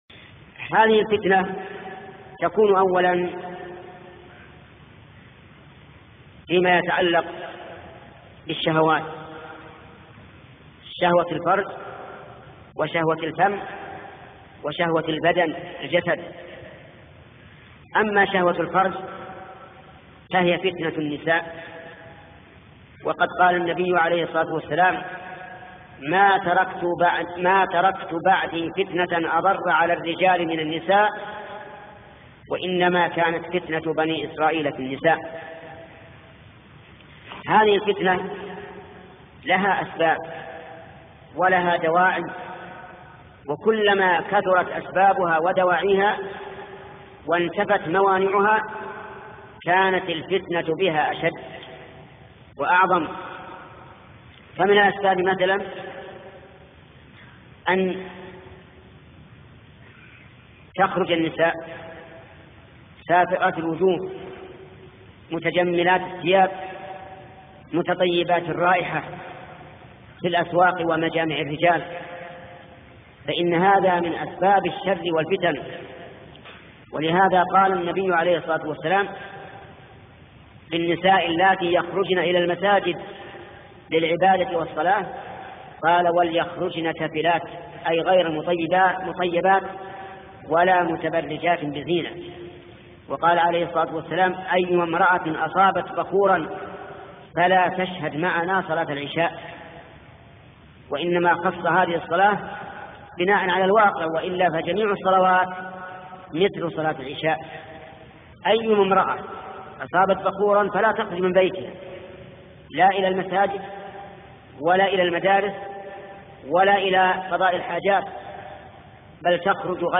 فتاوى من محاضرة موقف المسلم من الفتن - الشيخ محمد بن صالح العثيمين